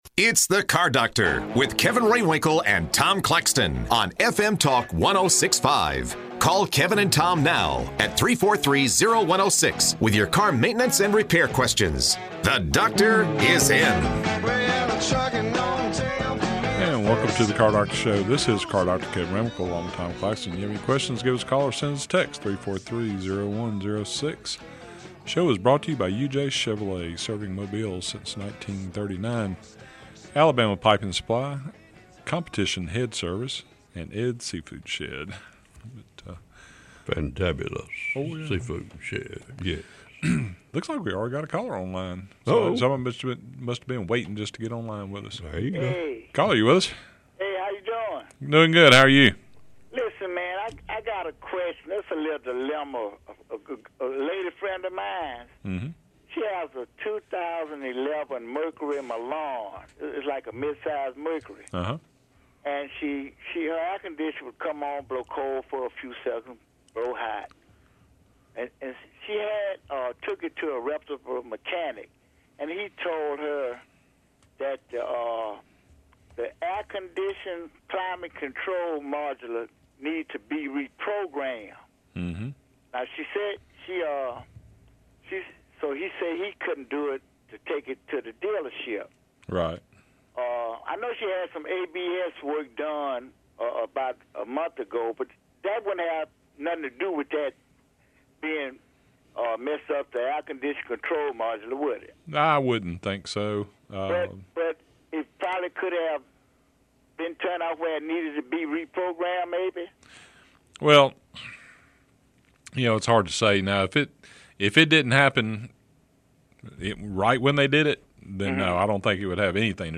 Automotive repair and racing experts